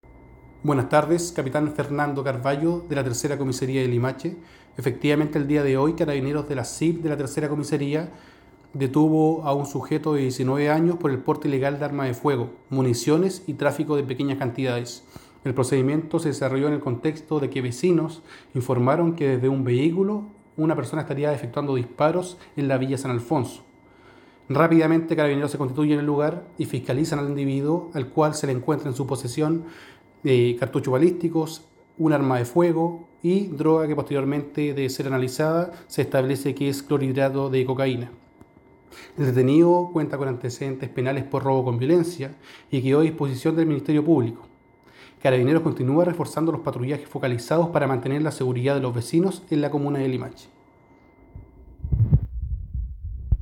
“Carabineros se constituye en el lugar y fiscalizan al individuo,  al cual se le encuentra en su posesión,  cartuchos balísticos, un arma de fuego y droga, que posteriormente después de ser analizada, se establece que es clorhidrato de cocaína”, detalló el oficial.